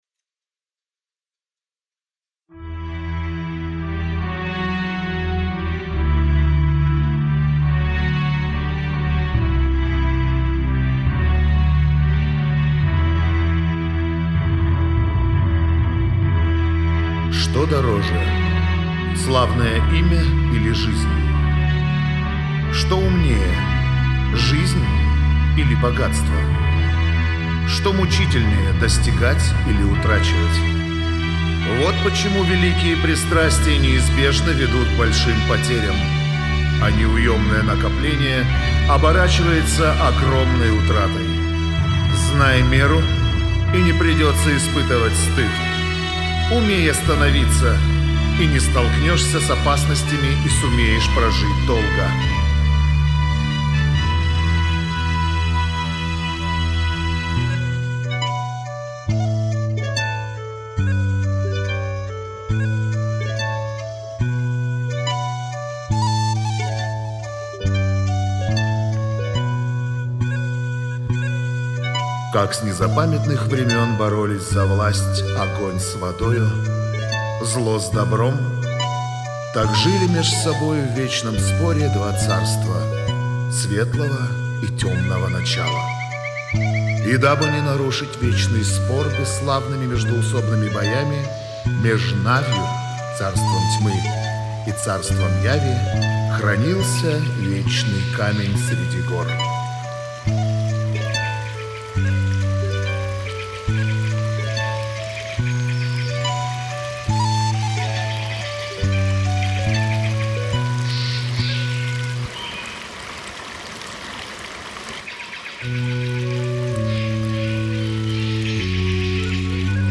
вокал
гитара